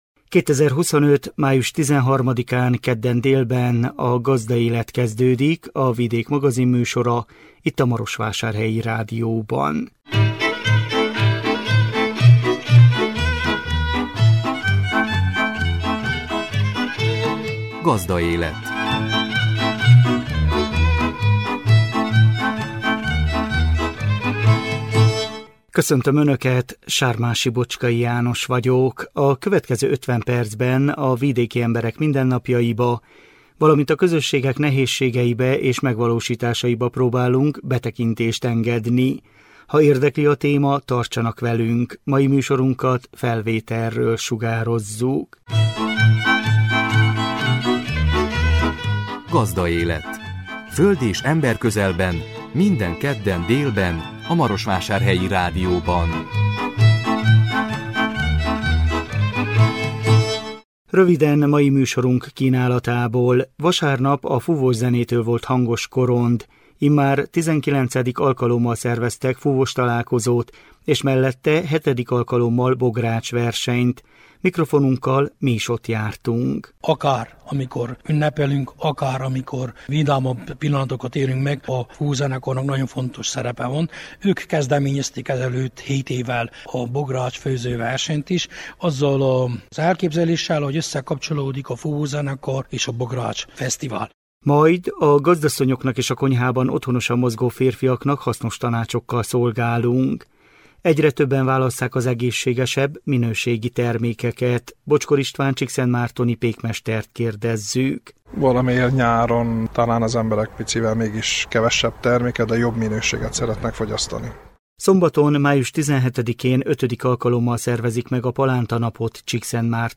A 2025 május 13-án jelentkező műsor tartalma: Vasárnap a fúvószenétől volt hangos Korond. Immár XIX. alkalommal szerveztek fúvóstalálkozót és mellette VII. alkalommal bogrács versenyt. Mikrofonunkkal mi is ott jártunk. Majd a gazdasszonyoknak és a konyhában otthonosan mozgó férfiaknak hasznos tanácsokkal szolgálunk.